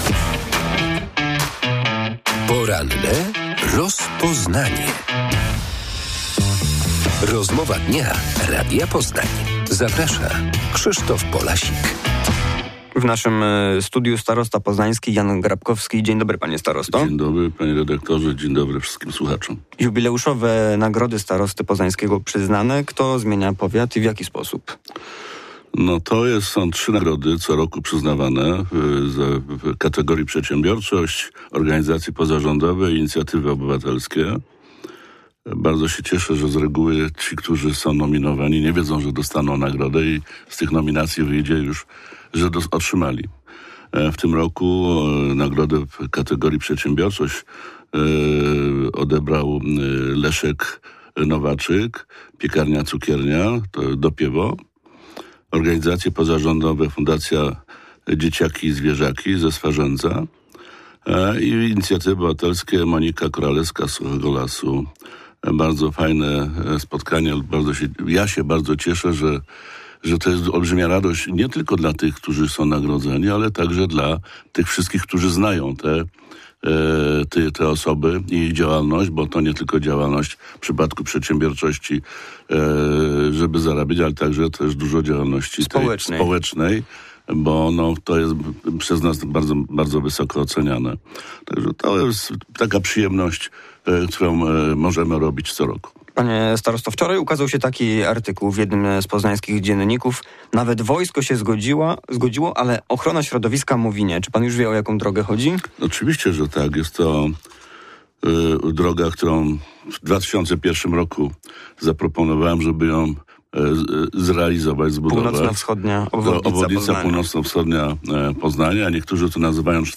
Polityk Koalicji Obywatelskiej skomentował w Porannym Rozpoznaniu Radia Poznań pomysł ministerstwa infrastruktury, aby to samorządy województw decydowały o transporcie autobusowym. Obecnie pieniądze z funduszu na PKS przydzielają wojewodowie.